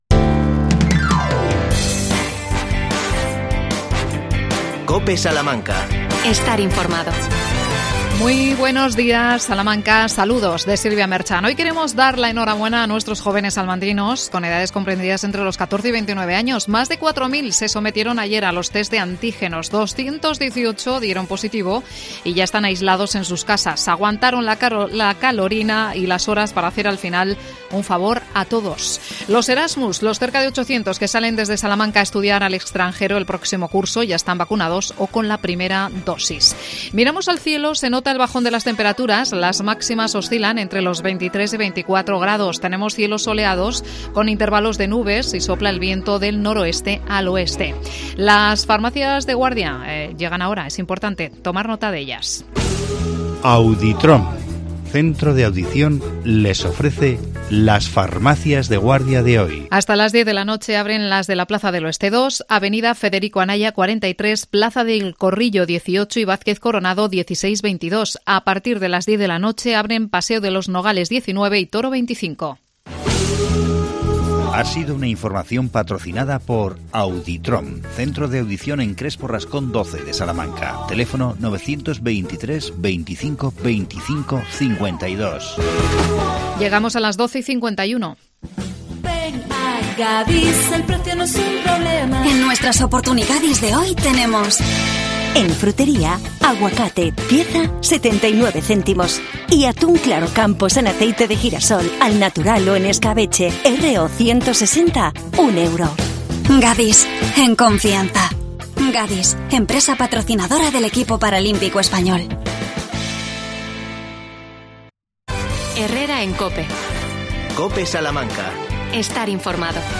50 años de Amor y Paz. Entrevistamos